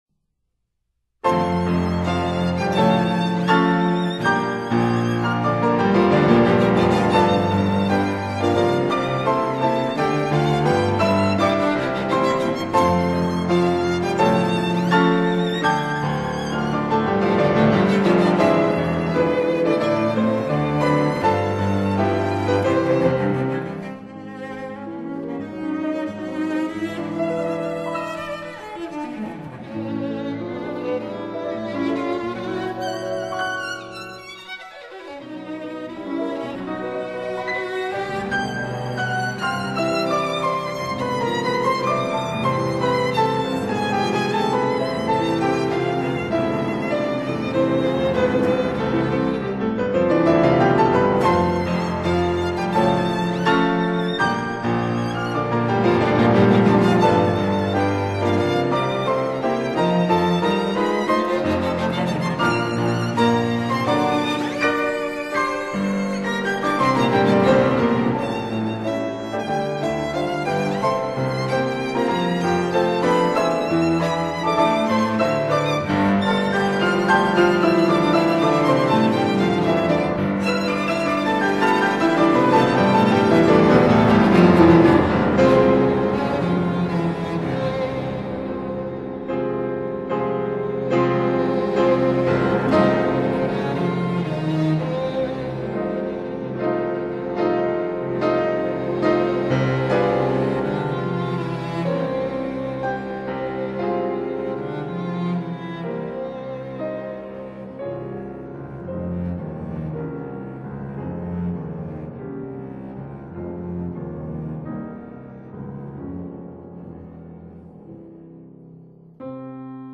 Allegro    [0:03:41.00]